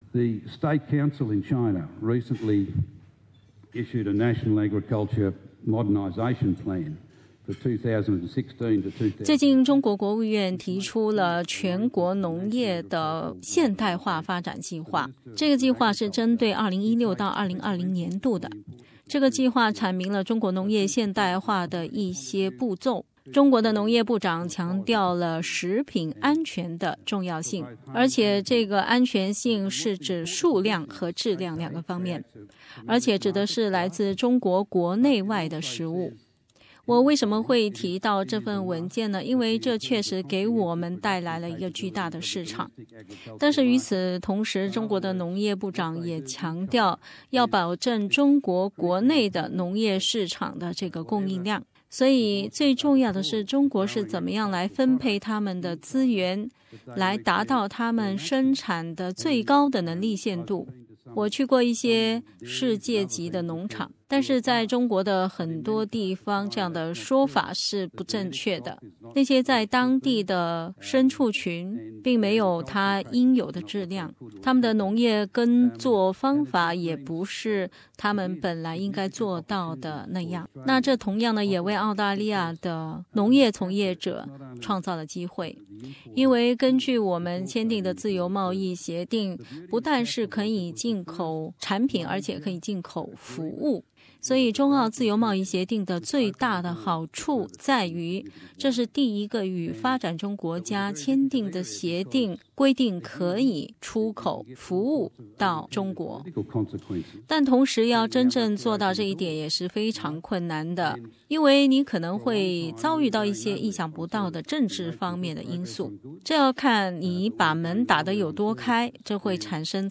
下面我们来分享澳大利亚前贸易部长Andrew Robb在悉尼澳大利亚-中国商务周演讲的部分内容：